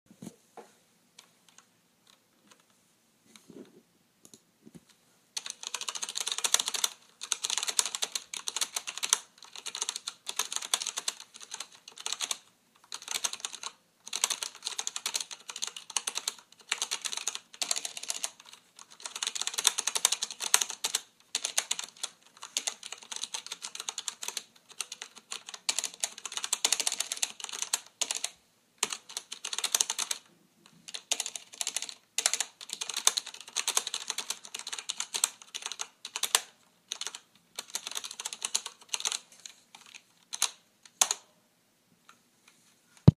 在键盘上打字
描述：在罗技G510键盘上打字。 由ZOOM Q3记录。
标签： 键盘 电脑 施莱本 罗技 写字 pc 塔斯塔夫 打字 G510
声道立体声